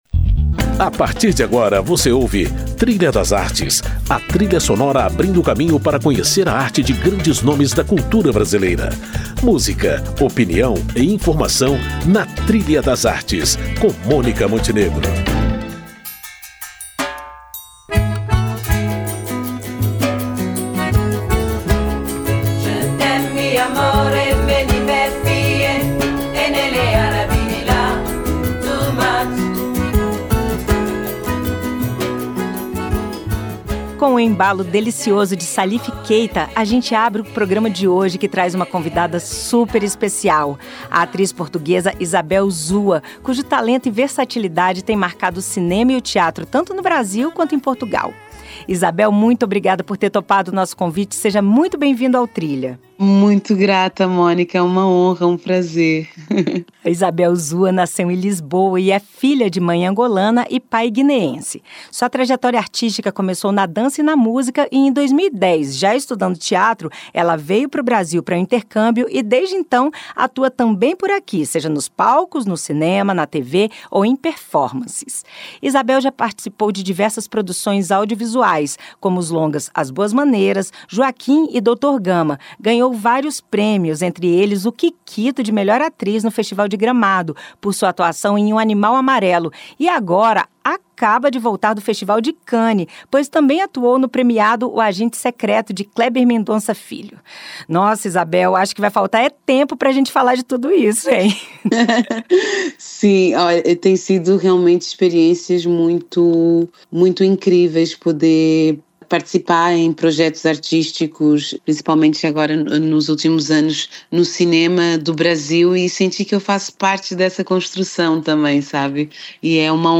Tudo isso ao som de uma trilha sonora que reflete sua multiculturalidade: